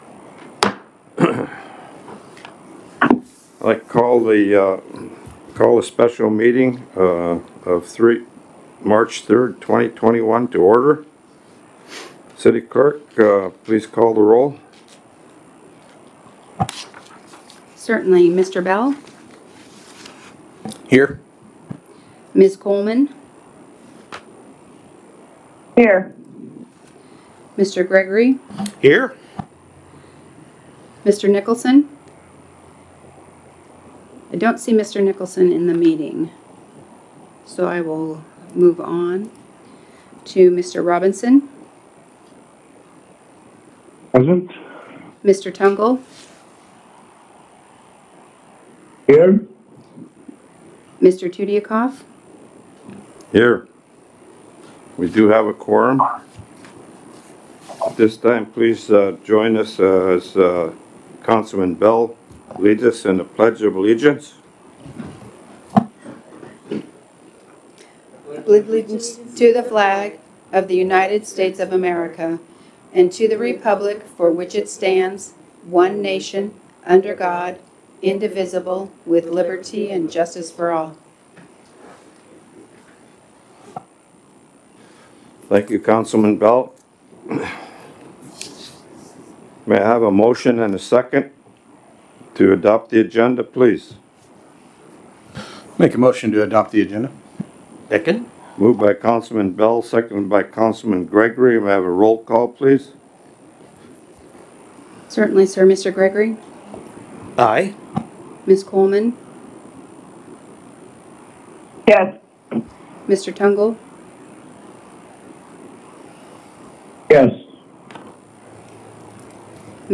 Special City Council Meeting - March 3, 2021 | City of Unalaska - International Port of Dutch Harbor